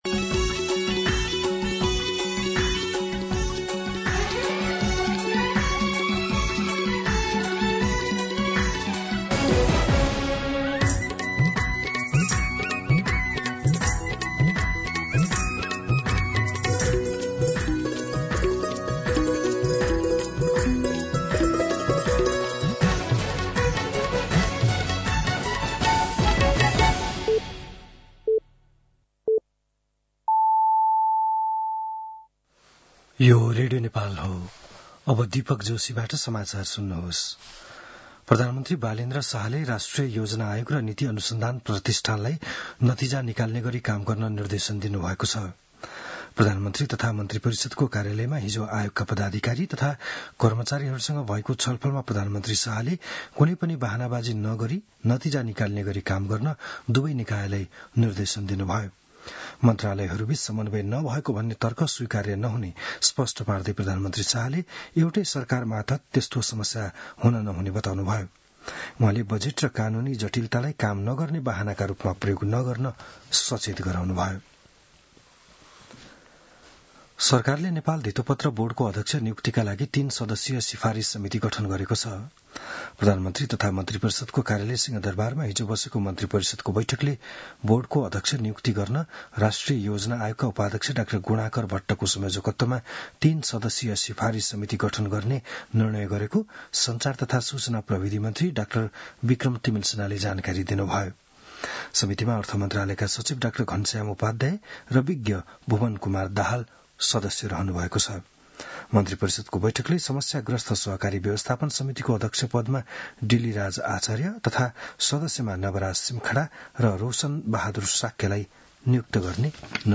बिहान ११ बजेको नेपाली समाचार : ९ वैशाख , २०८३